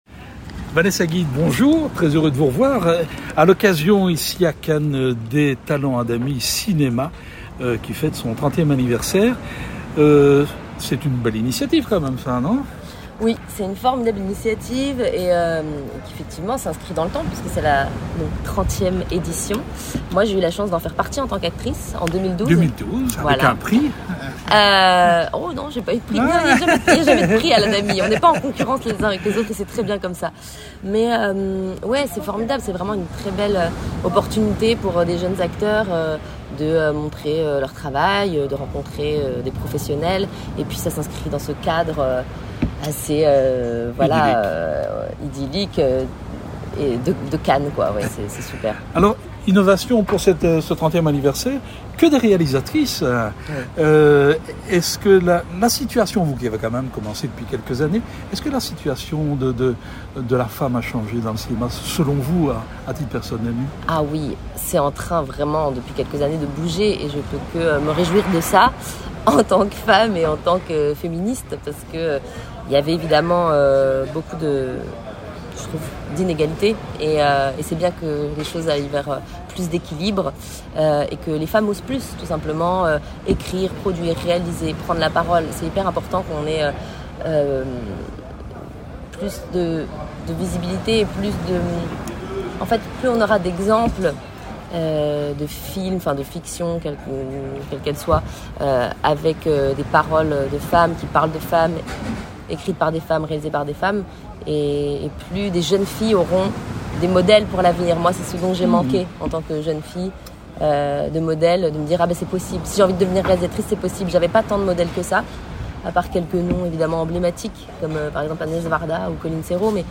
C’est avec toujours autant de plaisir que nous retrouvons sur la Croisette la jeune comédienne qui présente le court-métrage «Les Noyaux sans cerises» qu’elle vient de réaliser dans le cadre du programme Talents Adami Cinéma qui souffle cette année ses 30 bougies.